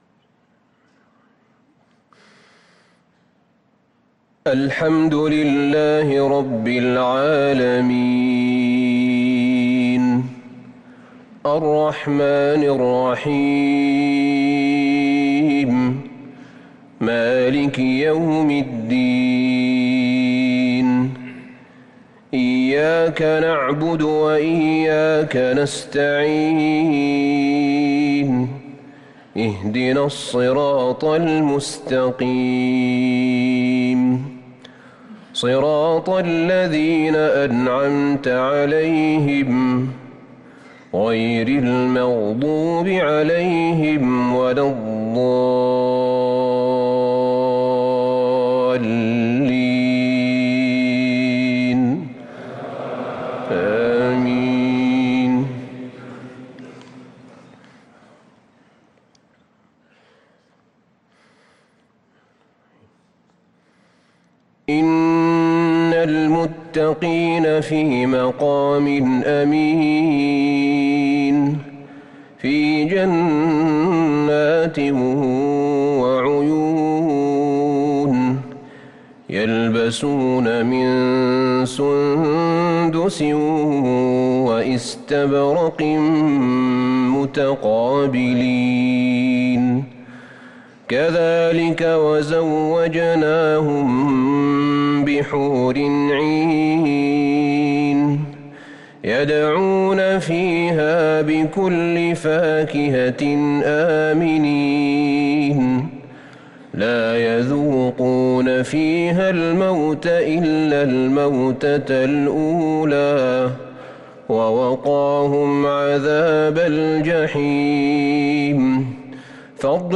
صلاة المغرب للقارئ أحمد بن طالب حميد 25 ذو القعدة 1443 هـ